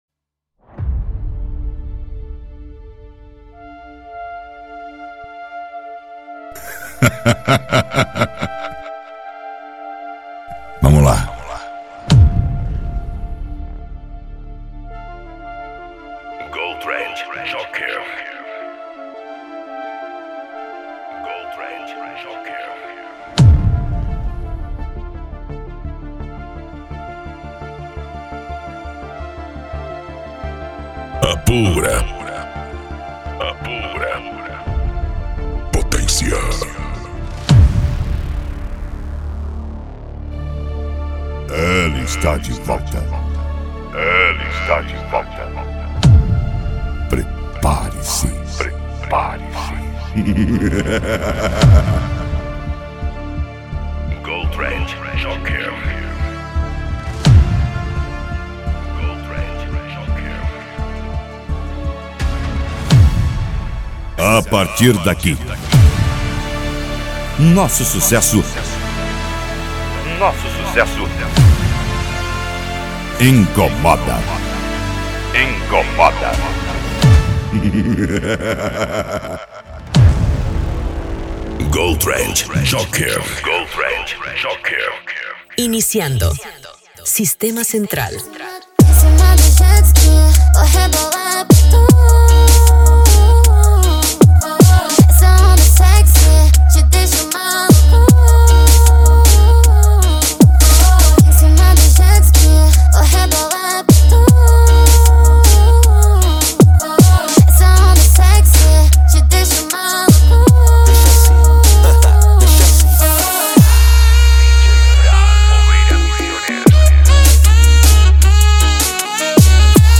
Arrocha
Funk
Remix